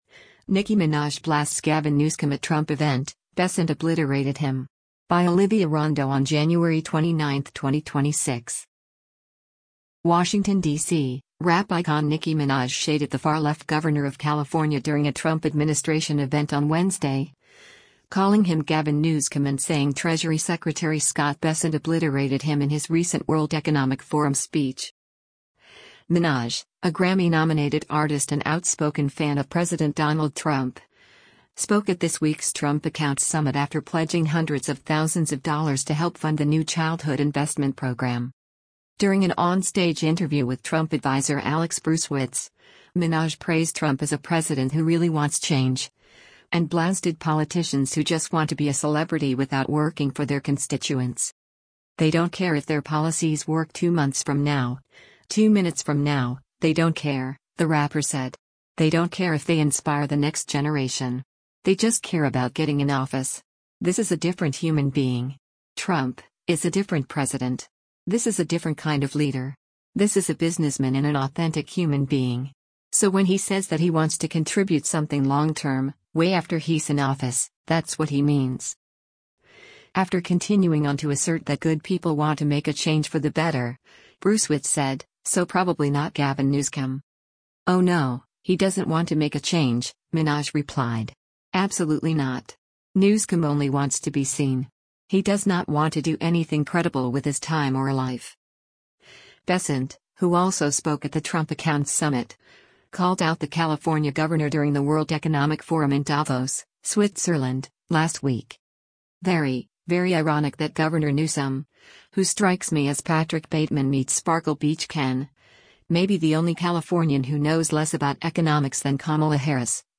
Minaj, a Grammy-nominated artist and outspoken fan of President Donald Trump, spoke at this week’s “Trump Accounts” summit after pledging hundreds of thousands of dollars to help fund the new childhood investment program.